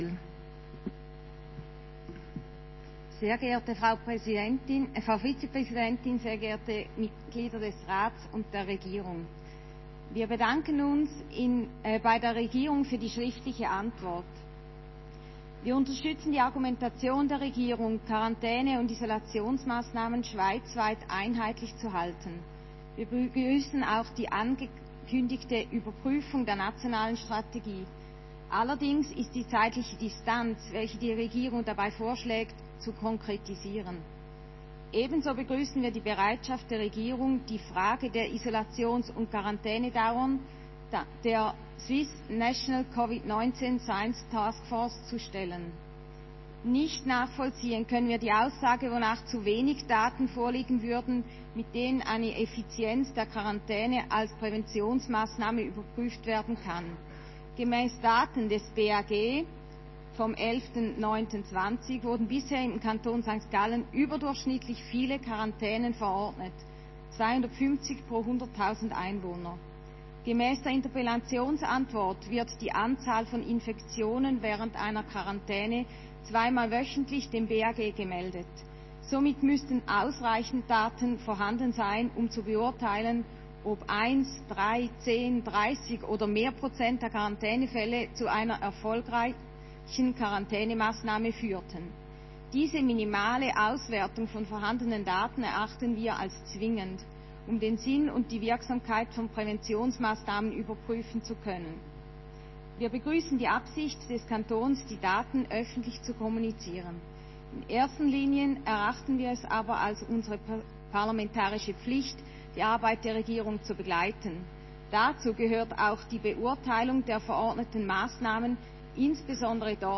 15.9.2020Wortmeldung
Session des Kantonsrates vom 14. bis 17. September 2020